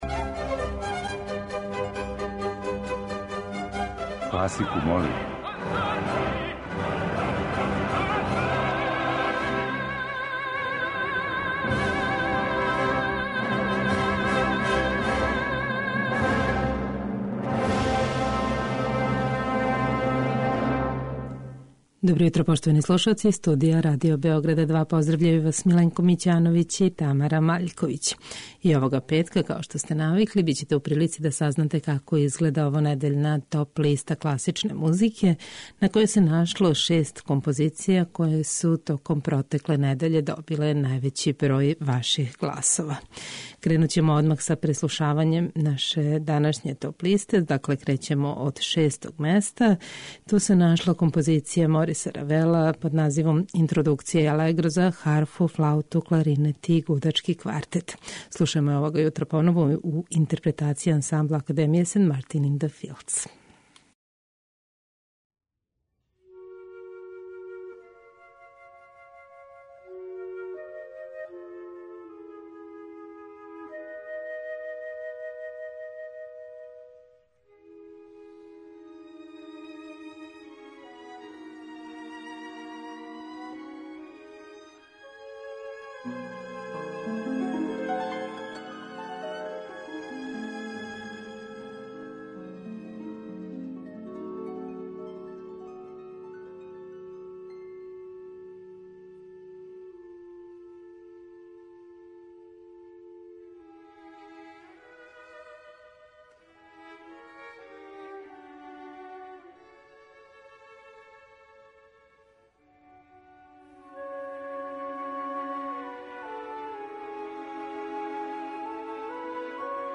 У завршној емисији овонедељног циклуса емисије 'Класику, молим', бићете у прилици да сазнате како изгледа овонедељна топ-листа класичне музике Радио Београда 2.